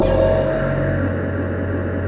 SI1 BAMBO00L.wav